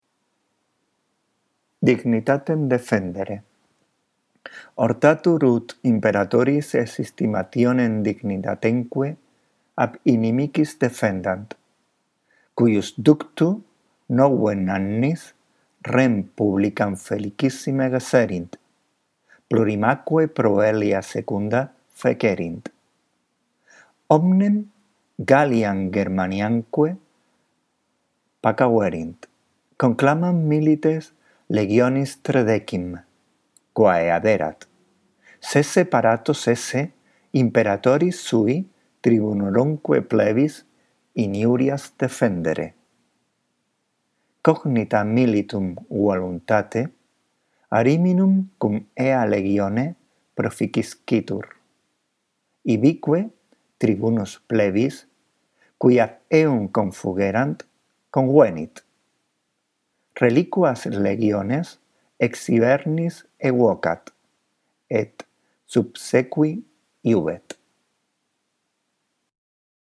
La audición de esta grabación puede ayudarte a mejorar tu lectura del latín: